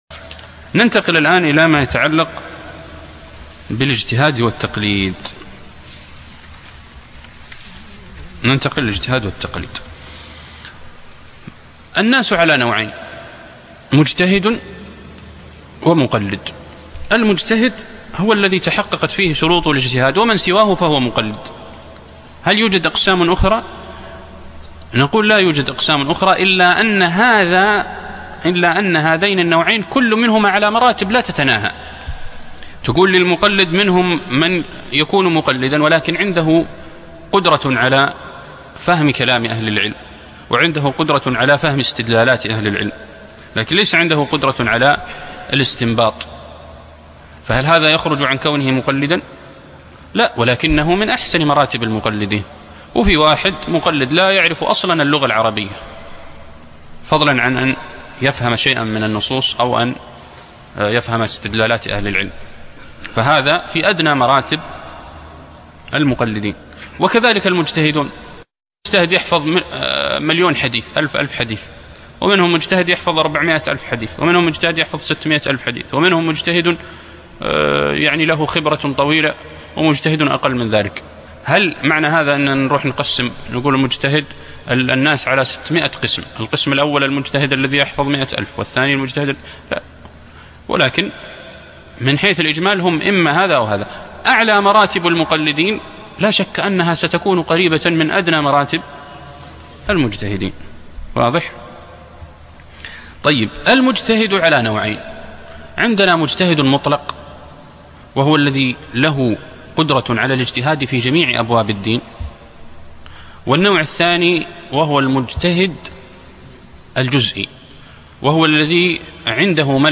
عنوان المادة الدرس ( 25)الاجتهاد والتقليد - أصول البناء الفقهي الحنبلي_التأهيل